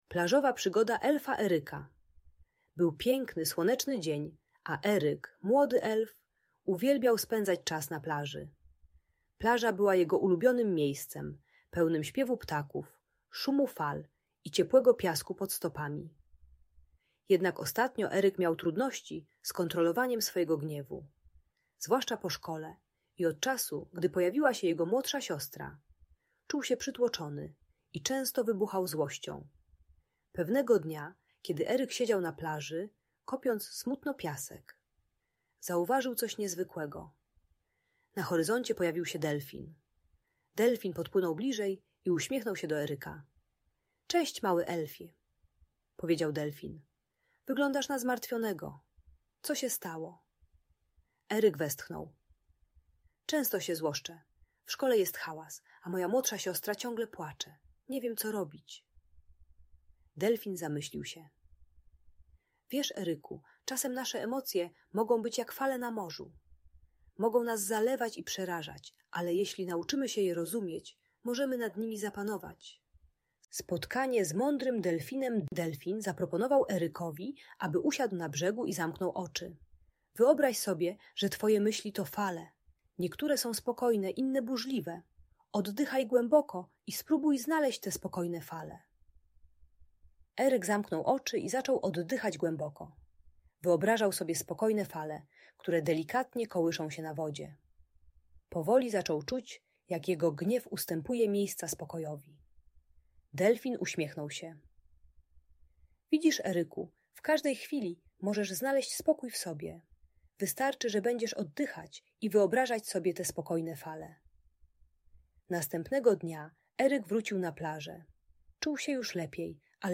Plażowa Przygoda Elfa Eryka - Bunt i wybuchy złości | Audiobajka